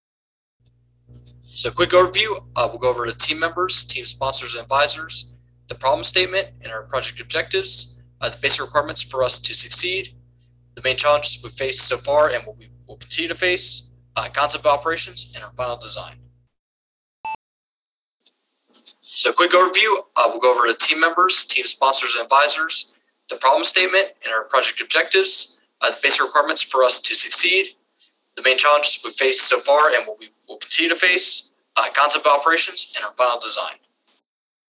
I did try lower settings for threshold & harmonics, but the hum is bad on this one.